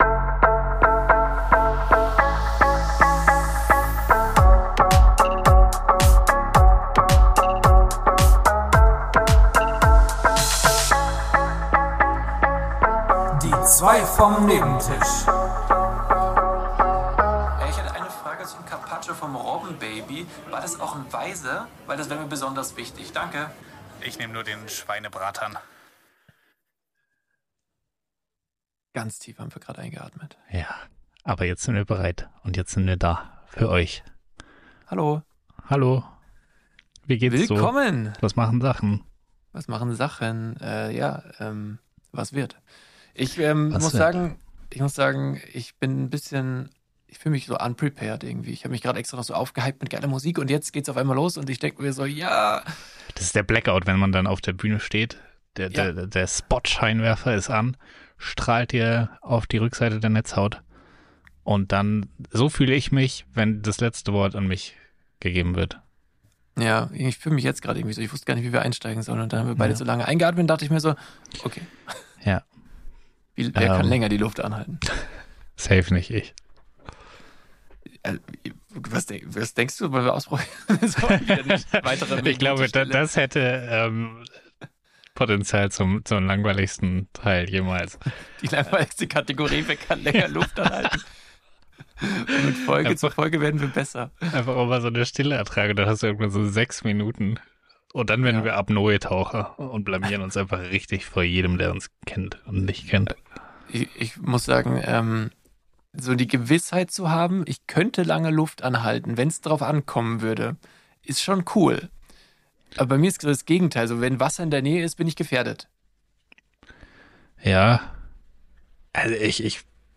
Beschreibung vor 2 Jahren Es war ein mal vor gar nicht all zu langer Zeit, da machten sich zwei mediocre white men am Nebentisch breit, sie sprachen viel sie sprachen lang gar freiwillig und ohne Zwang.